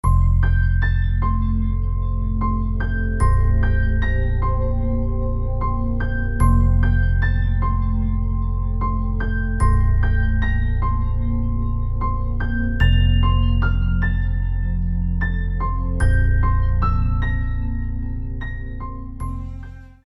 • Качество: 192, Stereo
инструментальные
колокольчики
простые